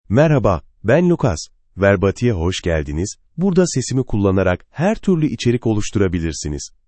MaleTurkish (Turkey)
LucasMale Turkish AI voice
Lucas is a male AI voice for Turkish (Turkey).
Voice sample
Listen to Lucas's male Turkish voice.
Lucas delivers clear pronunciation with authentic Turkey Turkish intonation, making your content sound professionally produced.